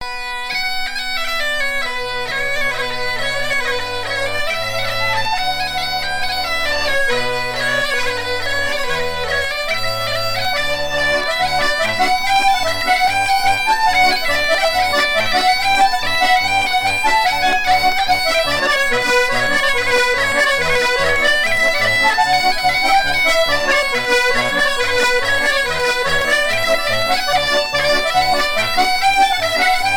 joueurs d'accordéon diatonique du Marais breton-vendéen
Chants brefs - A danser
danse : polka